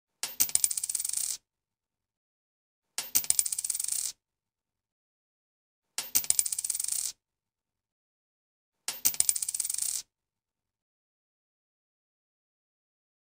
دانلود صدای افتادن یا ریختن سکه 1 از ساعد نیوز با لینک مستقیم و کیفیت بالا
جلوه های صوتی